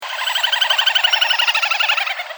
Малая поганка (Tachybaptus ruficollis (Pallas, 1764))
tachybaptus_ruficollis1.mp3